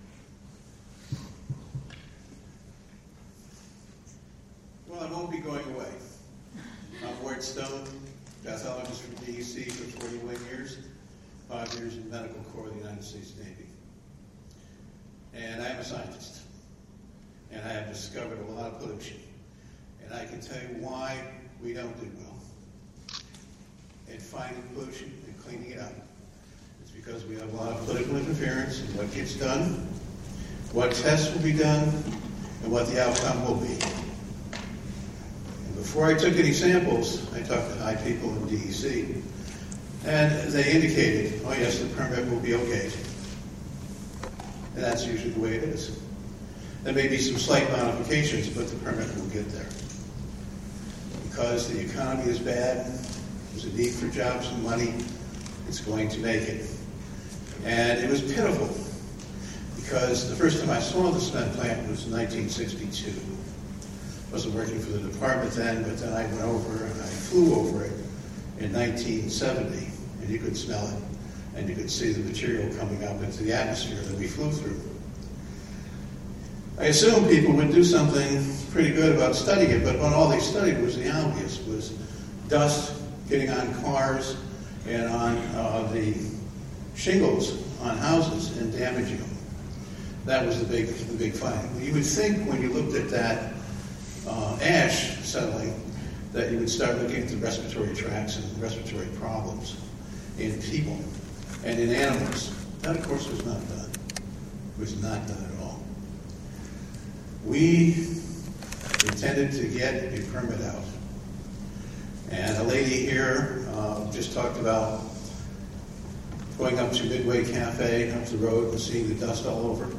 speaking at DOH Public Health Assessment hearing about Ravena's Lafarge cement plant.